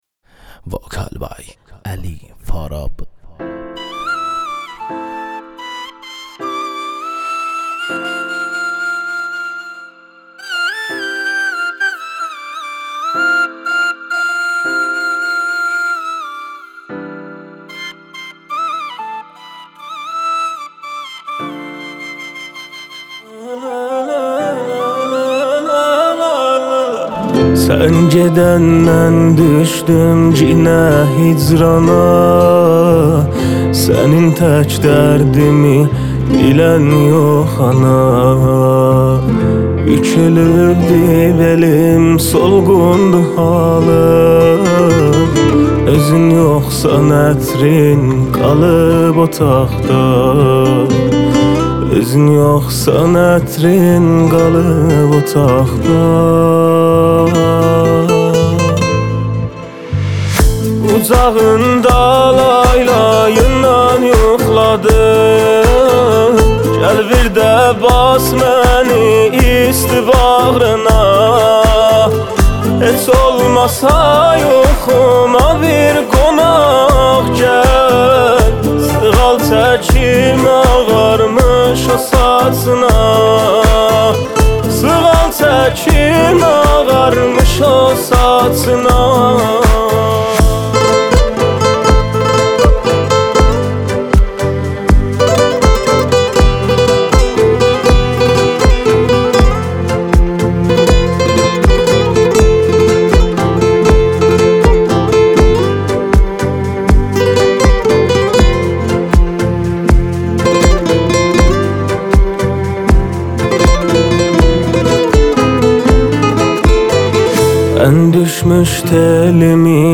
موزیک ترکی آذربایجانی